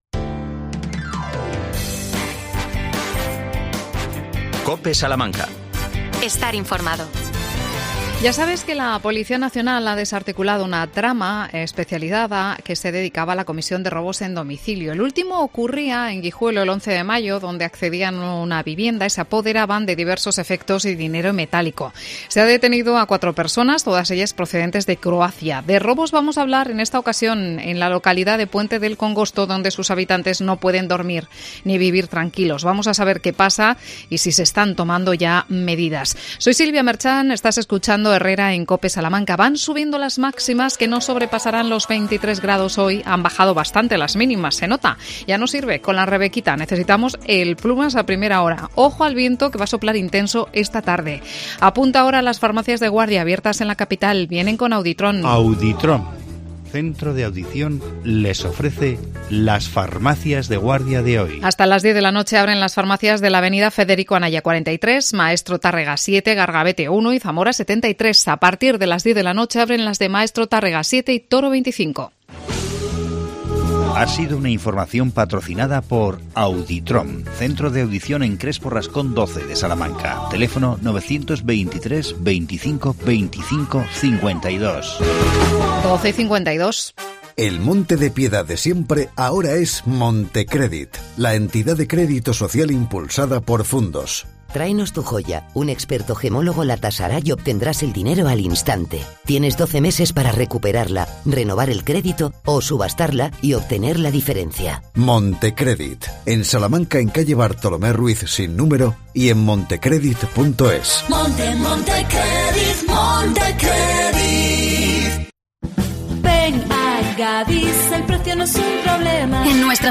AUDIO: Robos en Puente del Congosto. Hablamos con su alcalde Ángel Rosado.